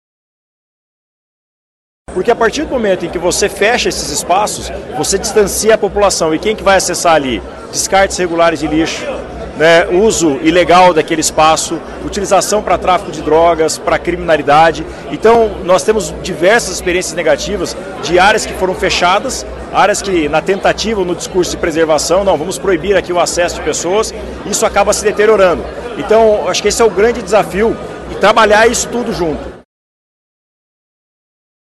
Quem também trouxe pautas para Curitiba foi o prefeito de Londrina, Tiago Amaral, que comentou sobre o desafio de se garantir preservação lado a lado com o acesso ao meio ambiente.